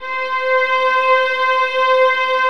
VIOLINS .2-L.wav